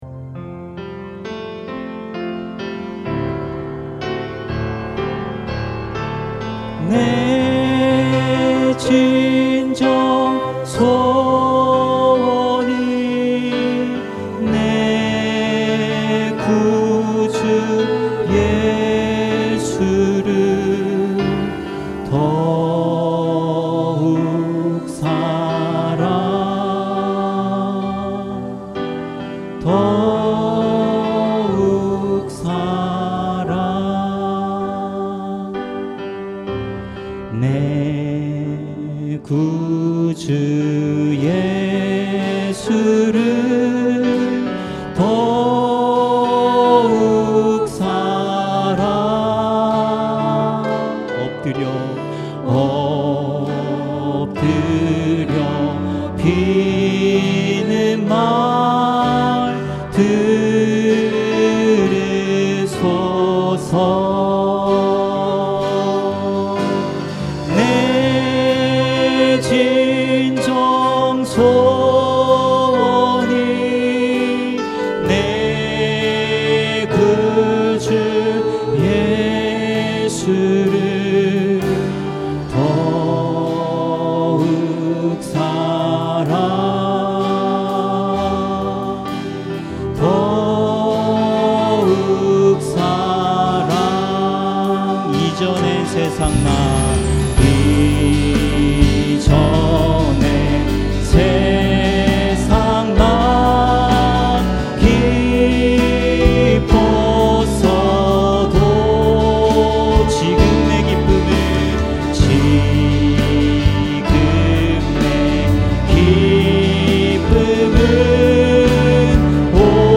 찬양 음악